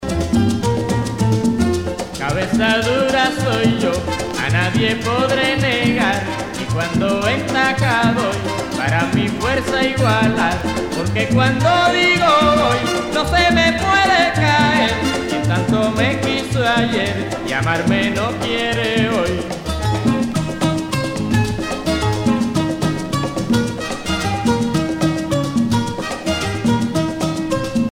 danse : guaracha
Pièce musicale éditée